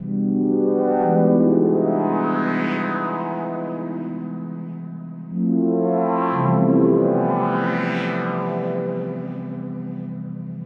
Index of /DESN275/loops/Loop Set - Drums- Downtempo Loops & Samples/Loops
RootOfUnity_90_E_SynthChords.wav